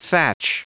Prononciation du mot thatch en anglais (fichier audio)
Prononciation du mot : thatch